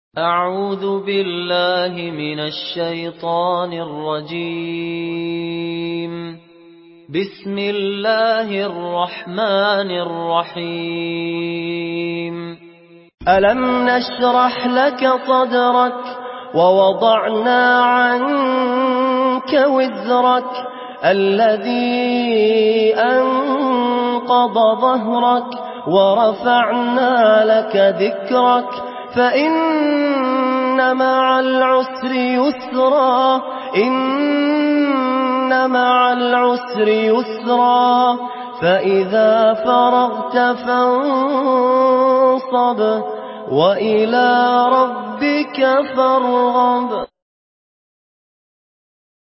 سورة الشرح MP3 بصوت فهد الكندري برواية حفص
مرتل